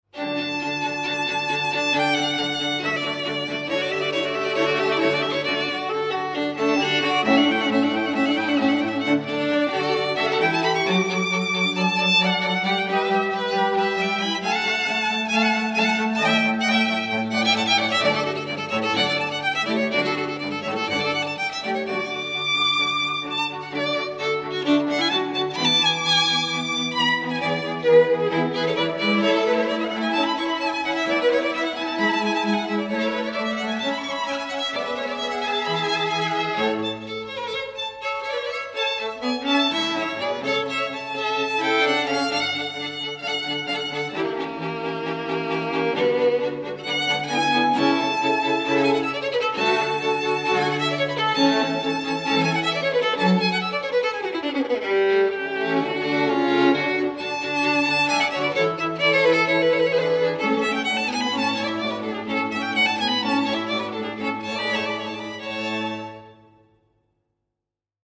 String Quartet based in the North-West